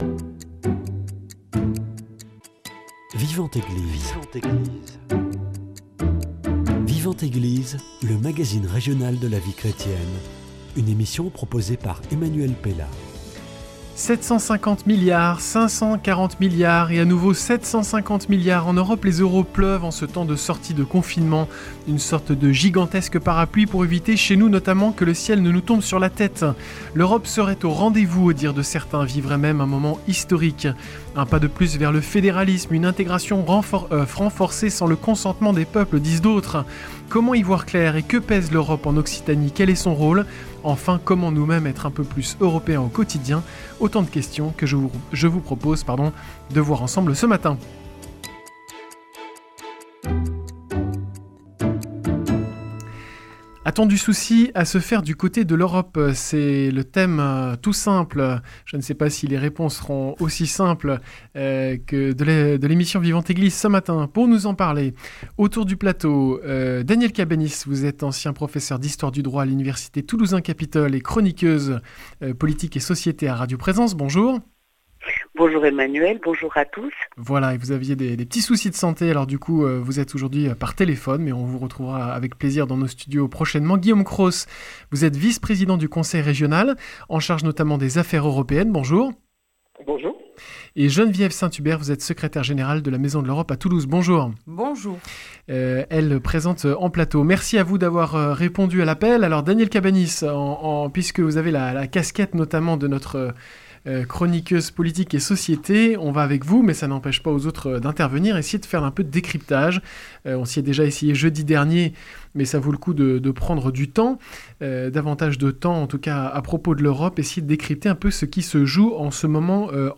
Guillaume Cros, vice-président du conseil régional d’Occitanie et en charge des affaires européennes